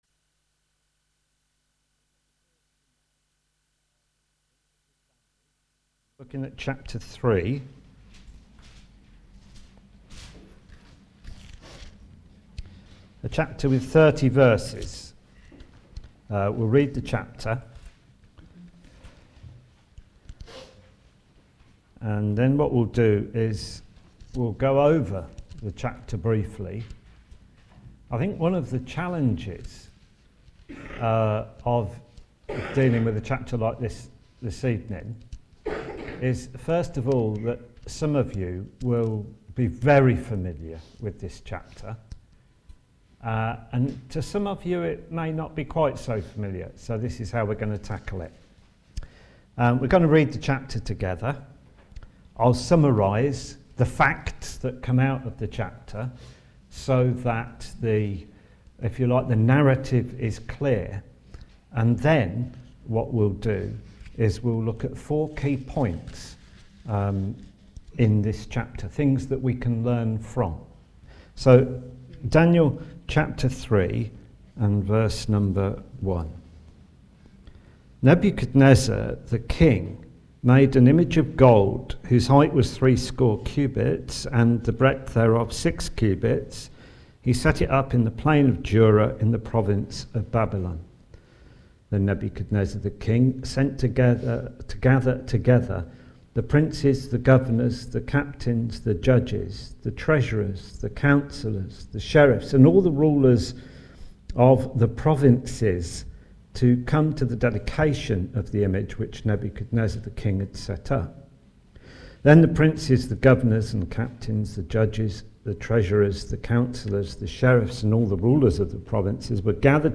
A group of independent Christians who gather to the Name of the Lord Jesus Christ in Northampton.
Service Type: Ministry